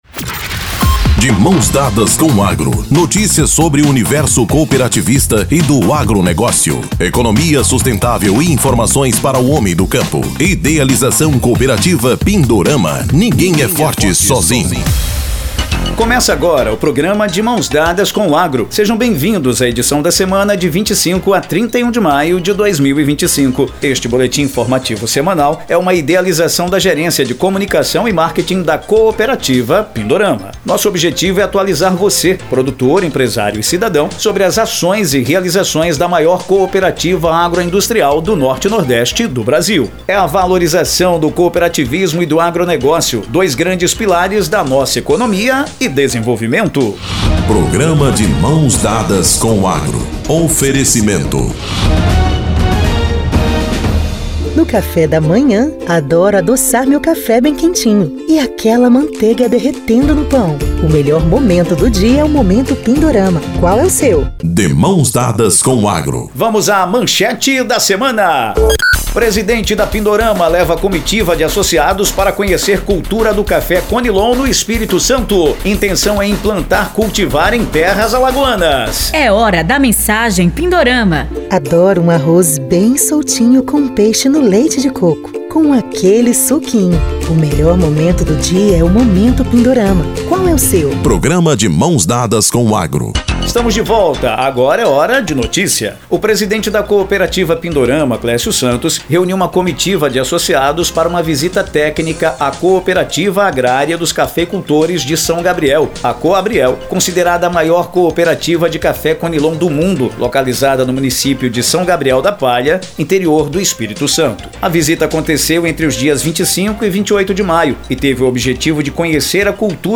Boletim